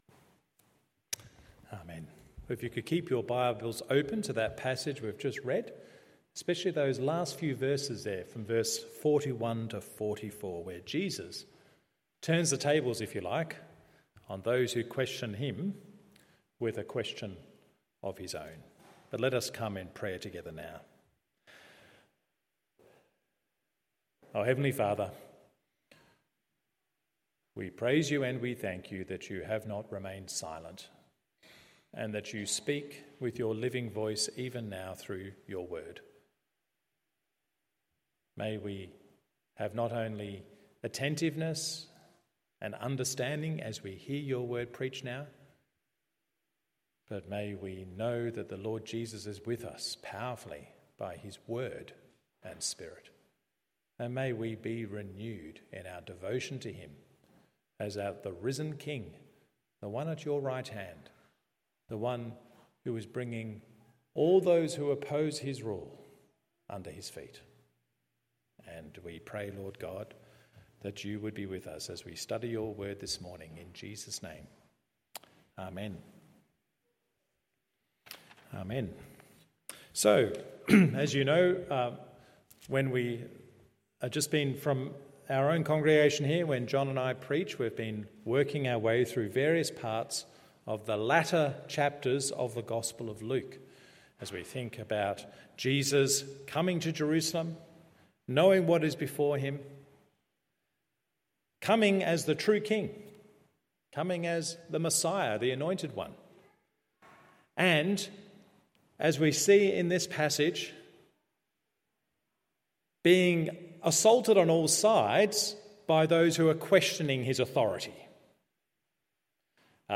Morning Service Psalm 110 Luke 20:20-44…